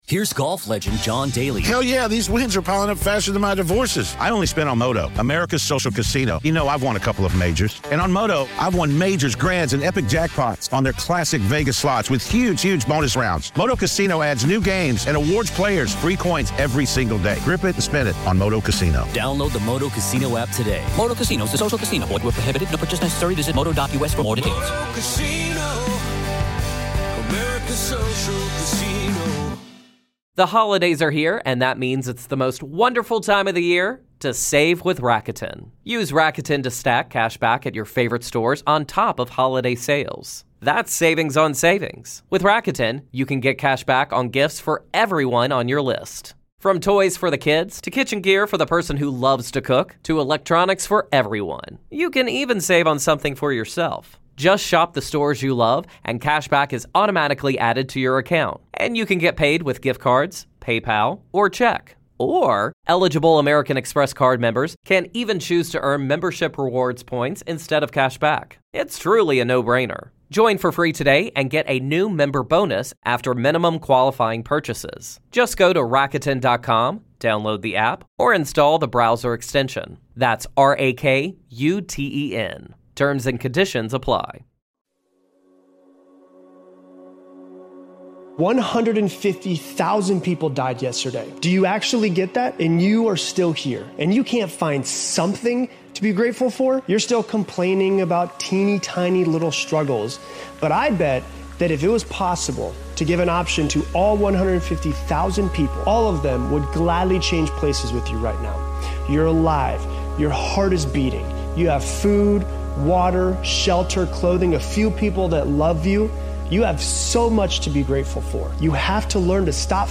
This episode will inspire you to make a fresh start and transform your life. Through a powerful collection of motivating speeches, you’ll learn that real change requires a complete reset—letting go of old habits, mindset, and limitations to unlock your full potential.